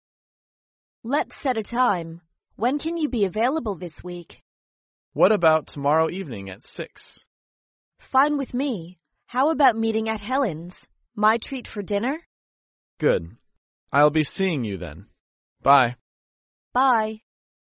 在线英语听力室高频英语口语对话 第55期:预约晚餐的听力文件下载,《高频英语口语对话》栏目包含了日常生活中经常使用的英语情景对话，是学习英语口语，能够帮助英语爱好者在听英语对话的过程中，积累英语口语习语知识，提高英语听说水平，并通过栏目中的中英文字幕和音频MP3文件，提高英语语感。